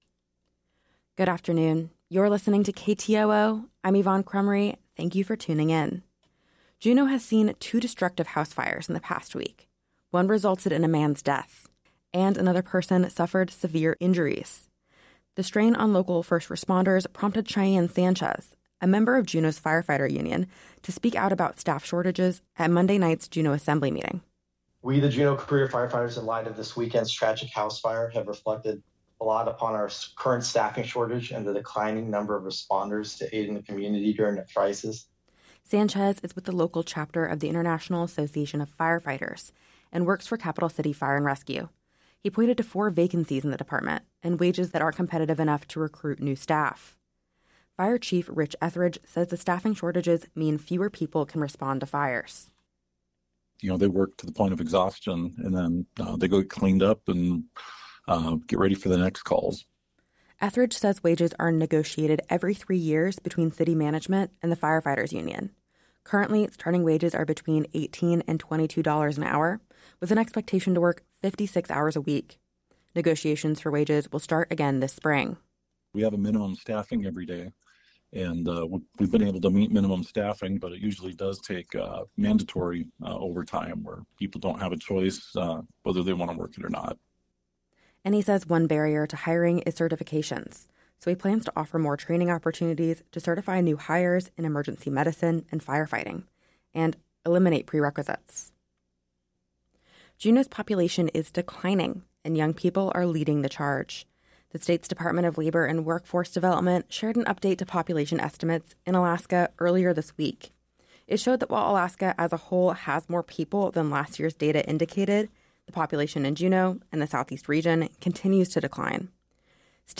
Newscast – Wednesday, Jan. 8, 2025